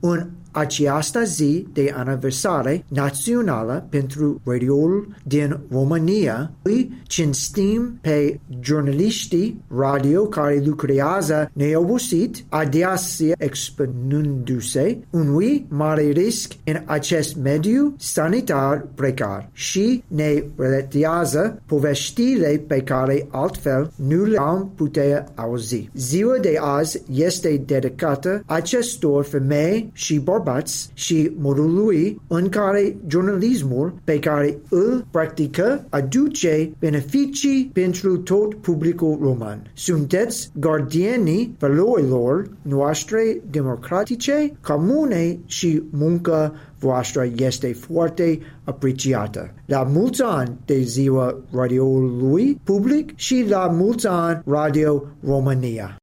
Un prim mesaj de felicitare a venit de la Ambasada Statelor Unite, rostit în limba română de Shane Dixon, adjunctul şefului misiunii diplomatice a Statelor Unite ale Americii la Bucureşti: